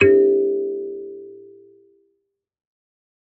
kalimba2_wood-G3-mf.wav